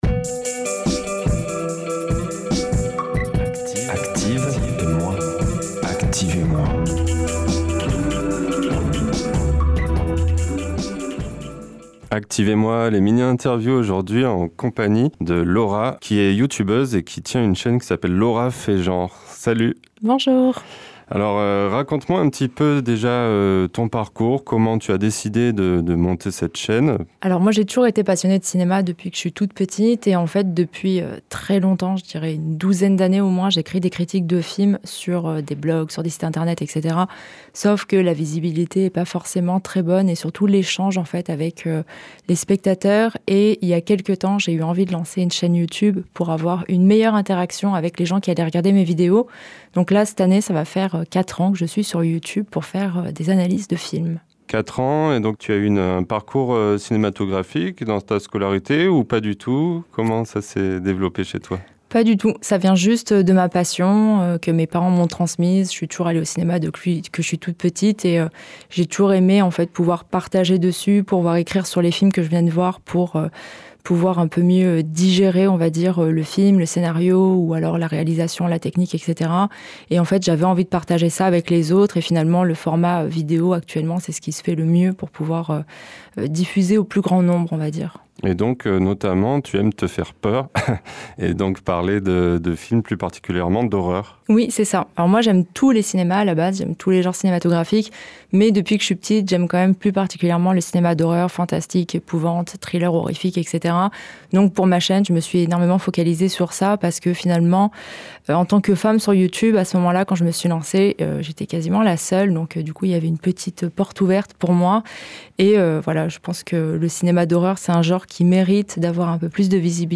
Entretien réalisé par